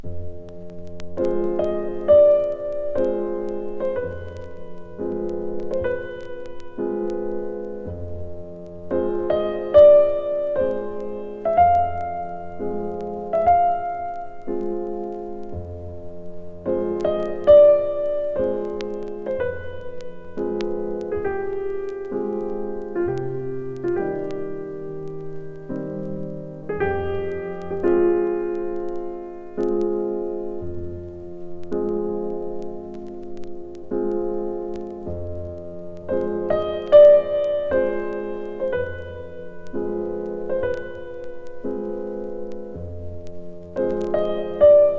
この人のサティはどれもがゆっくり＝遅い演奏。静かな雰囲気とトーンの重さは、一音の噛み締め具合が変わり、音自体をつぶさに広い集めたくなるような印象。本盤は第1集、「グノシエンヌ」「舞踏への小序曲」「天国の英雄的な門」他収録。
Classical, Modern　Netherlands　12inchレコード　33rpm　Stereo